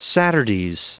Prononciation du mot saturdays en anglais (fichier audio)
Prononciation du mot : saturdays